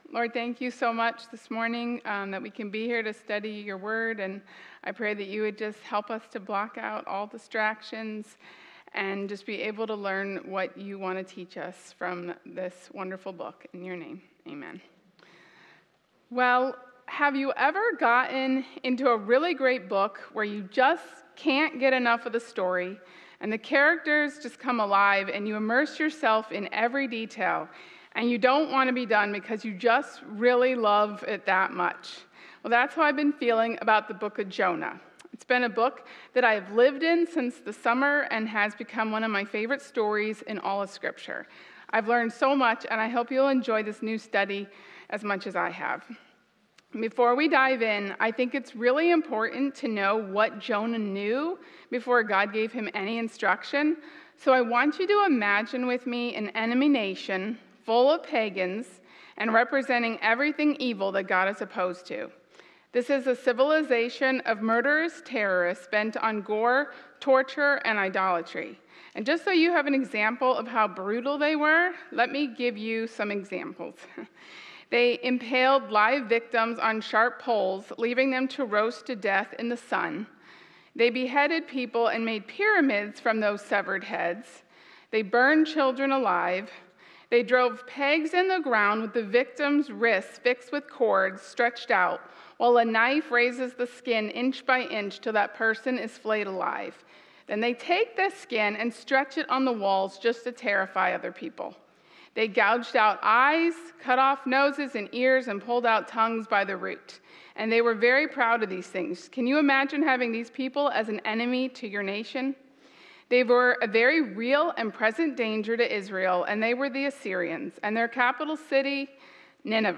Insight for Women Bible Study Lectures